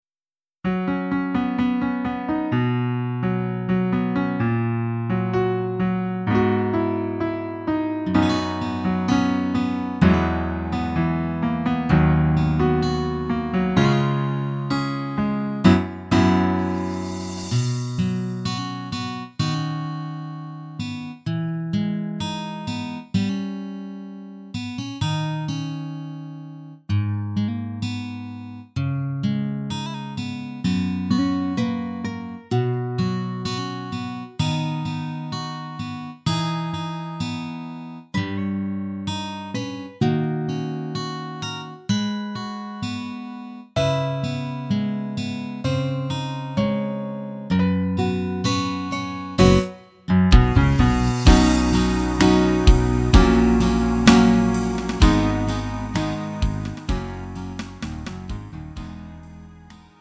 음정 원키 4:00
장르 가요 구분 Lite MR
Lite MR은 저렴한 가격에 간단한 연습이나 취미용으로 활용할 수 있는 가벼운 반주입니다.